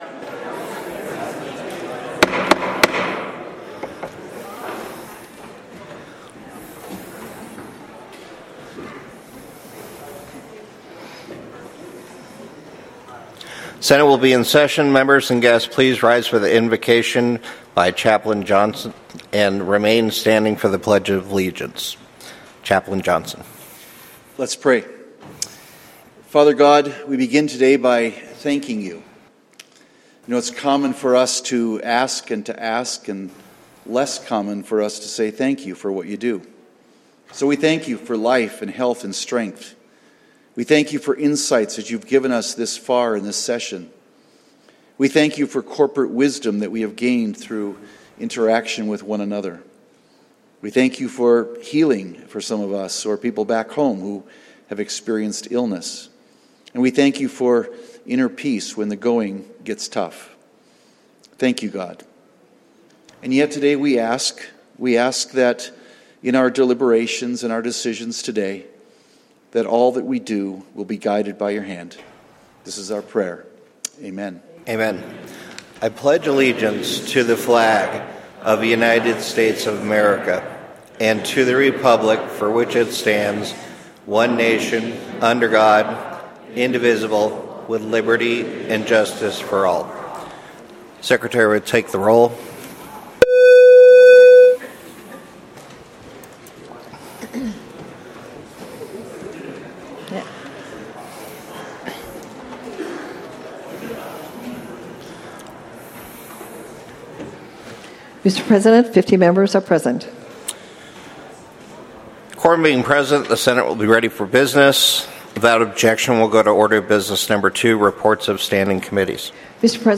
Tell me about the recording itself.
Senate Floor Session